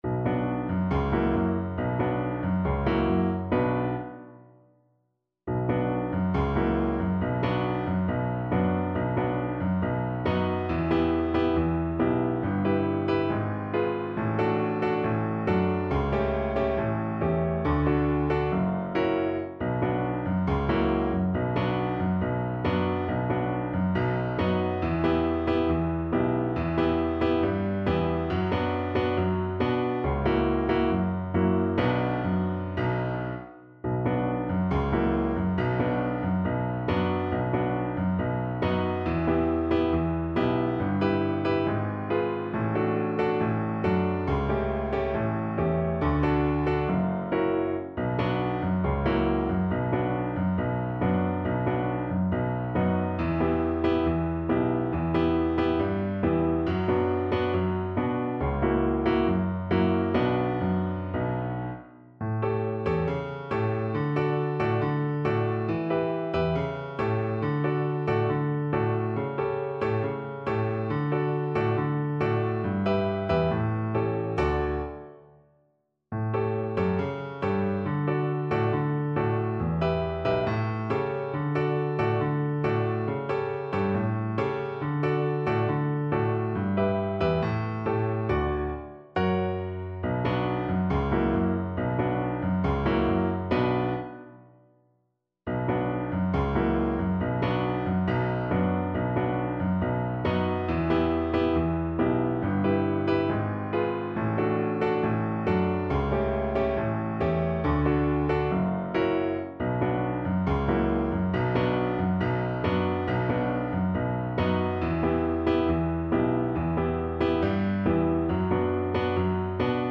Free Sheet music for Violin
D major (Sounding Pitch) (View more D major Music for Violin )
Steadily =c.69
2/4 (View more 2/4 Music)
Violin  (View more Intermediate Violin Music)
World (View more World Violin Music)
Brazilian Choro for Violin